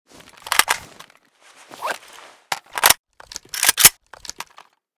akm_empty_reload.ogg.bak